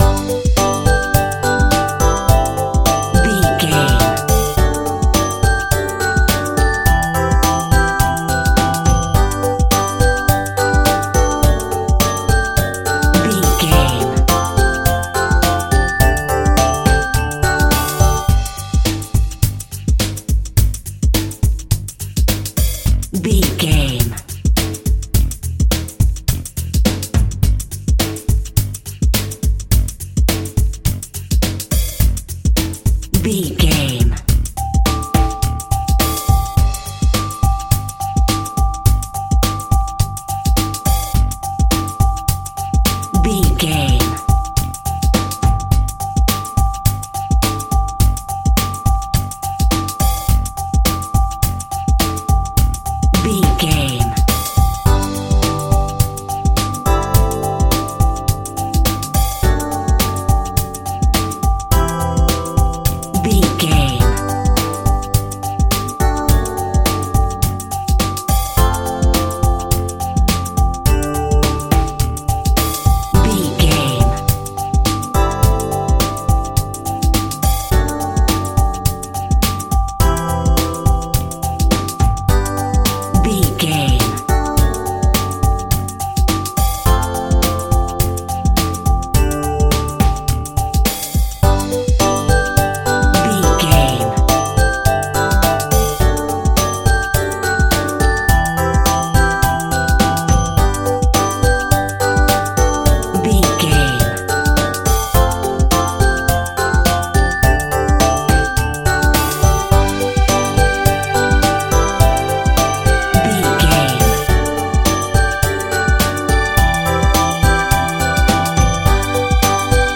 Uplifting
Ionian/Major
DOES THIS CLIP CONTAINS LYRICS OR HUMAN VOICE?
kids music
xylophone
strings
drums
bass guitar
piano.